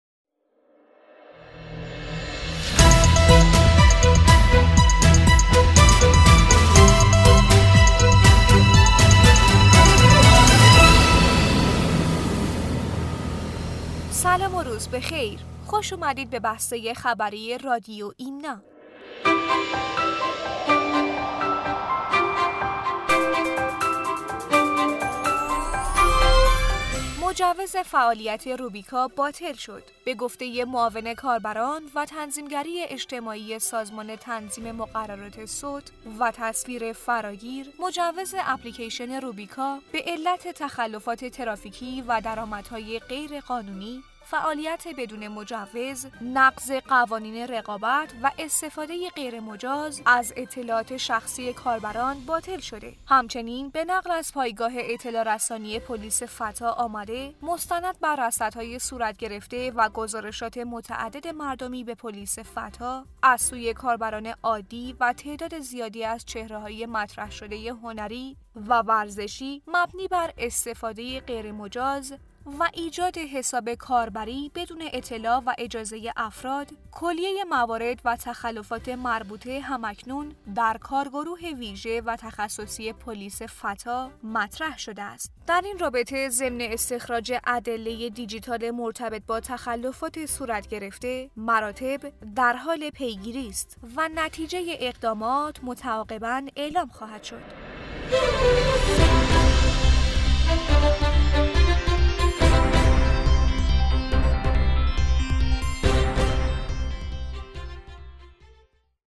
بسته خبری رادیو ایمنا/